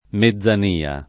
[ me zz an & a ]